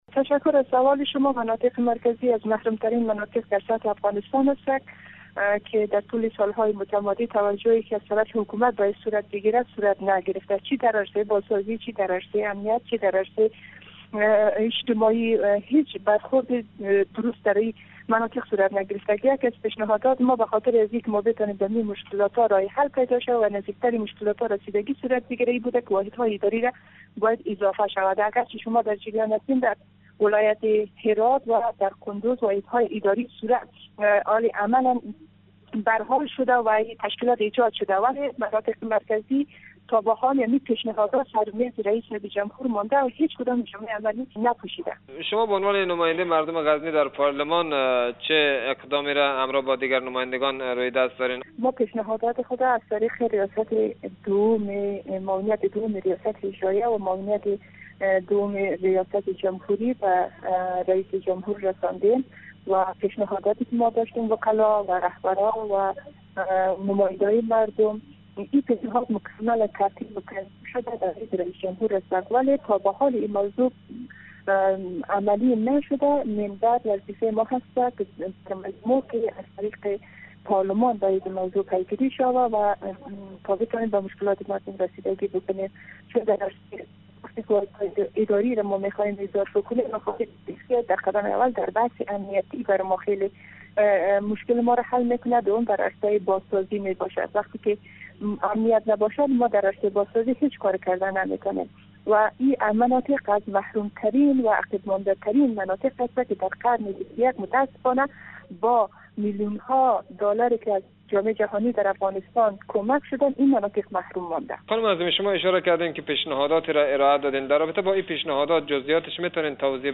نماینده مردم غزنی در پارلمان افغانستان می گوید
مصاحبه